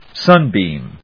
音節sún・bèam 発音記号・読み方
/ˈsʌˌnbim(米国英語), ˈsʌˌnbi:m(英国英語)/